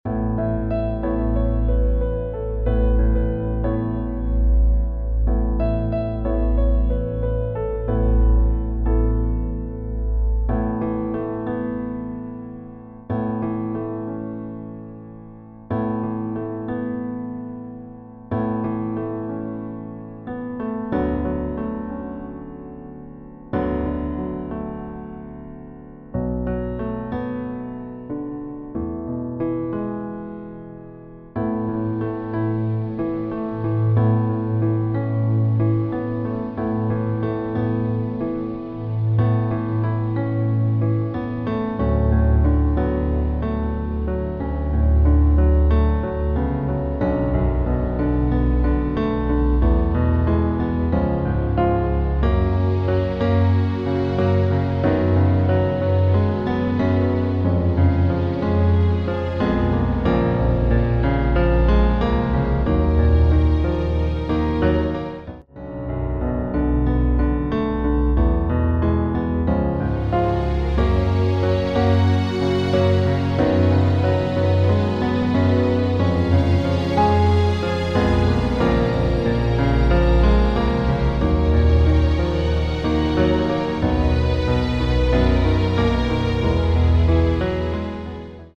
Demo in A Dur:
• Das Instrumental beinhaltet NICHT die Leadstimme
Klavier / Piano